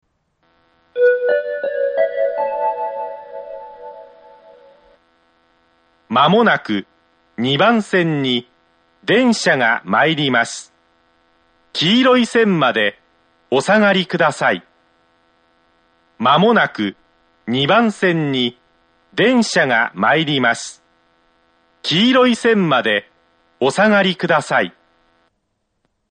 ２番線接近放送
yokosuka-2bannsenn-sekkinn1.mp3